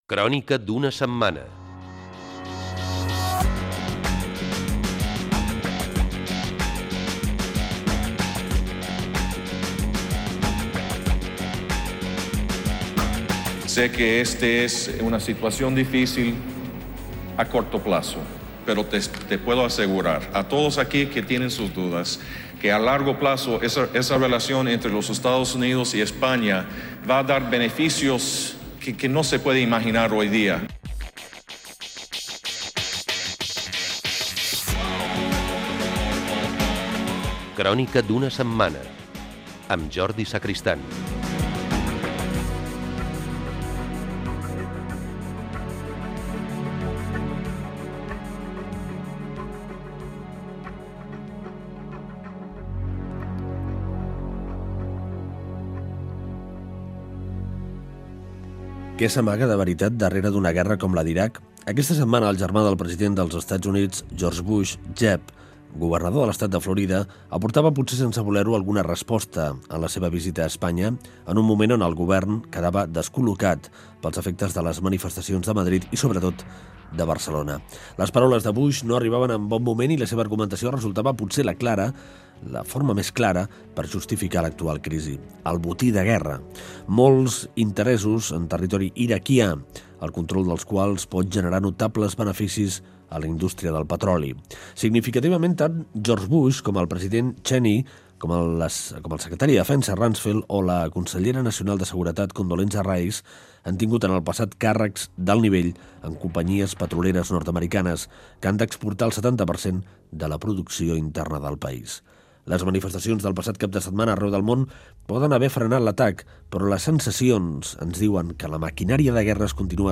La guerra d'Iraq. Entrevista al periodista Manuel Campo Vidal. Gènere radiofònic Informatiu